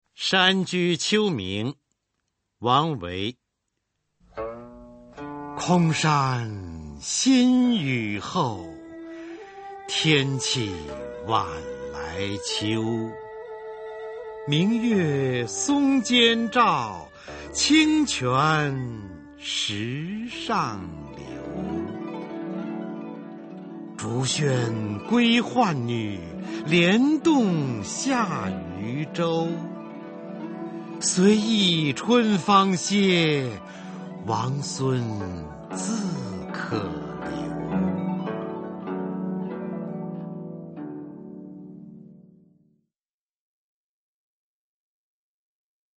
[隋唐诗词诵读]王维-山居秋暝（男） 配乐诗朗诵